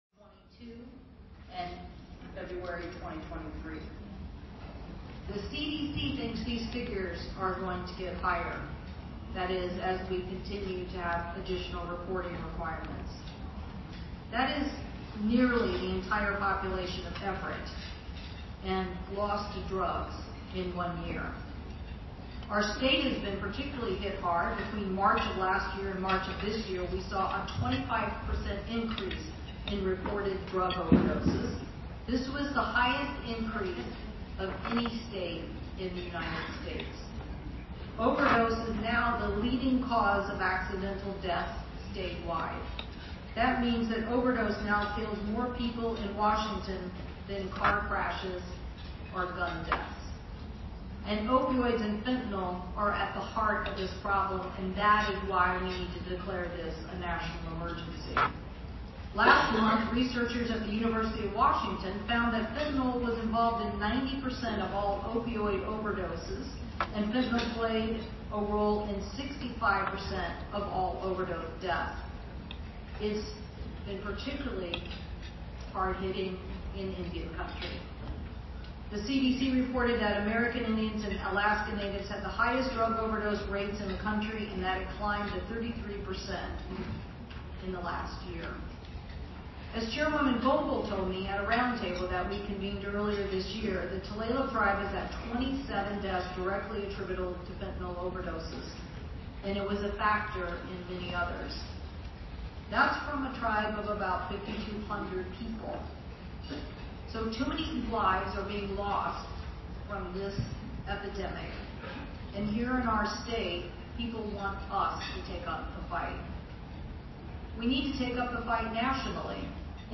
TULALIP, WA – Wednesday, U.S. Senator Maria Cantwell (D-WA) delivered an address at the National Tribal Opioid Summit, a gathering of approximately 900 tribal leaders, health care workers, and first responders from across the country.